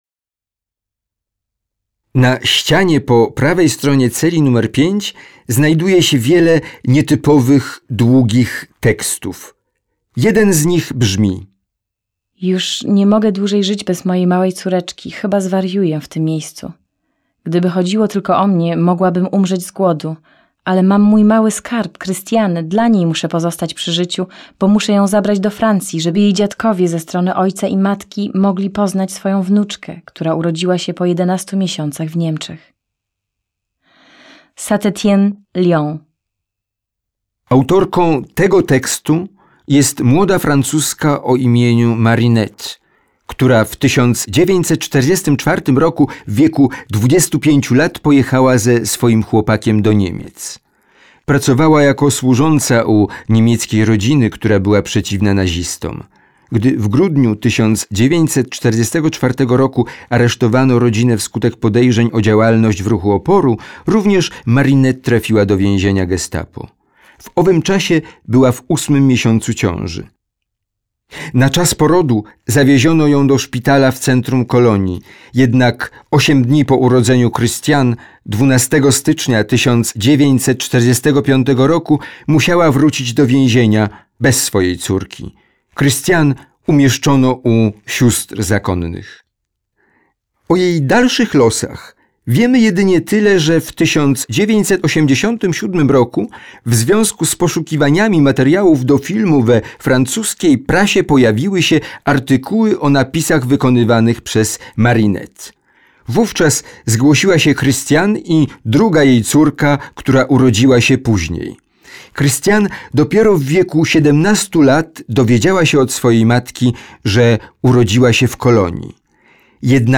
Synchronsprecher, Native Sprecher, Hörbuch/Hörspiel, Computerspiel, Dubbing
Kein Dialekt
Sprechprobe: Sonstiges (Muttersprache):